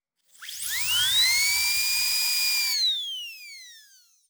DaCave_SFX_HC_556_Odontology_6.wav